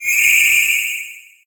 Cri de Larvadar dans Pokémon HOME.